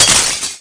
SOUND / BACKGROUND / GLASS / PLINK1.WAV
PLINK1.mp3